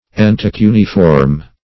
Search Result for " entocuneiform" : The Collaborative International Dictionary of English v.0.48: Entocuneiform \En`to*cu*ne"i*form\, Entocuniform \En`to*cu"ni*form\, n. [Ento- + cuneiform, cuniform.]